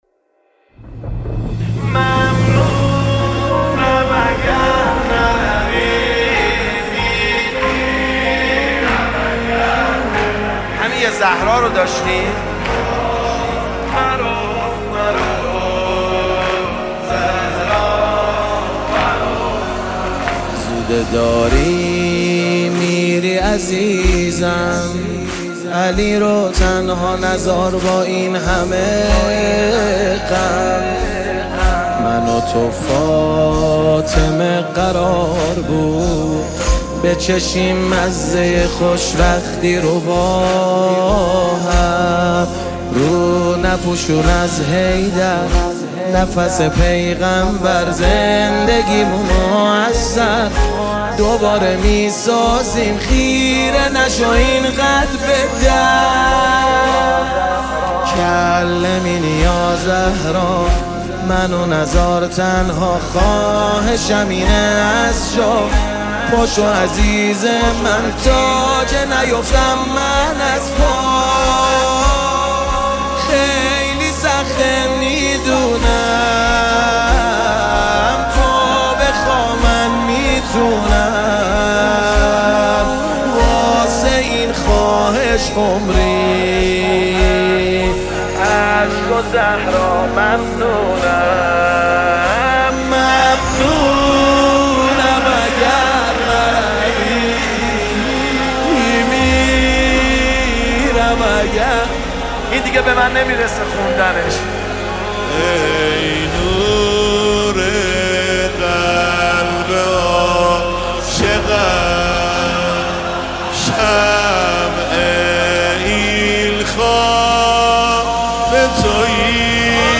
نماهنگ دلنشین
مداحی مذهبی